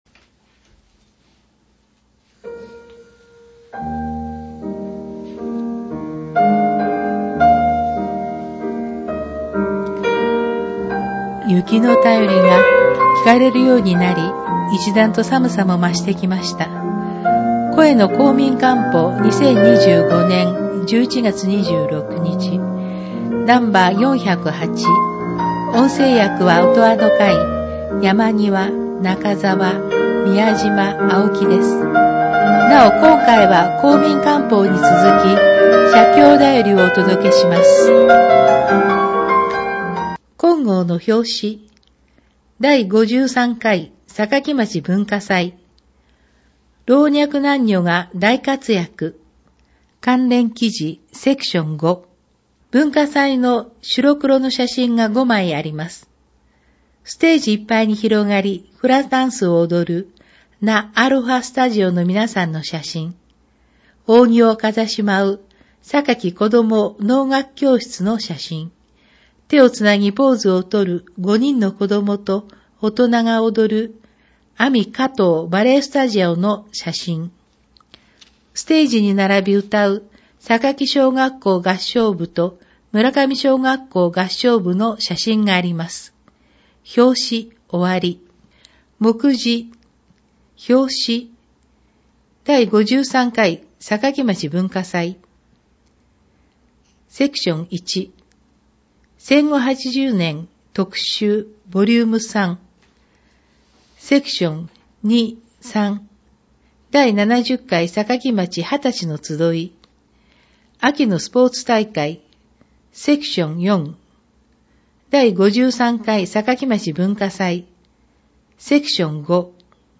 音訳版ダウンロード(制作：おとわの会）